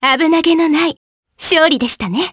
Selene-Vox_Victory_jp.wav